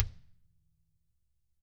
DW FELT PD-L.wav